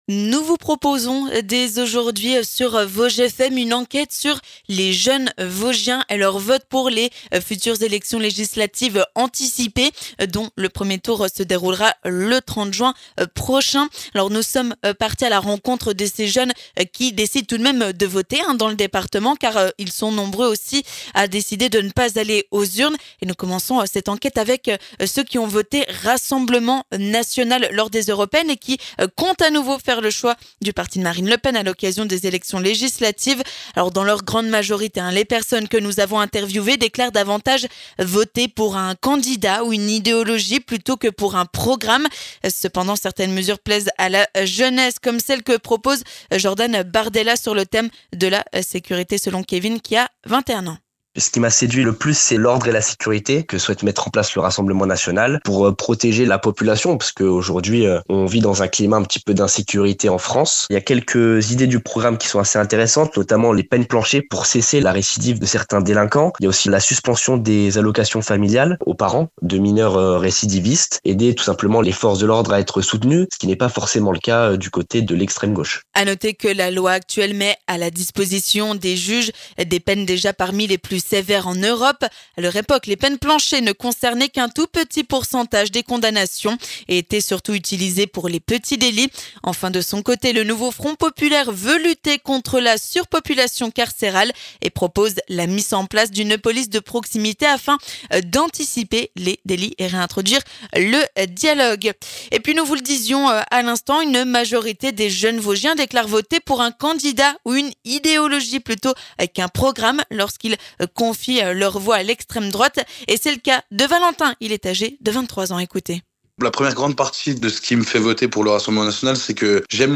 Nous débutons, sur Vosges FM, une enquête pour comprendre les votes de jeunes dans le département aux approches des élections législatives anticipées. Nous débutons cette enquête en donnant la parole aux 18-24 qui ont décidé de voter pour le Rassemblement national lors du prochain scrutin.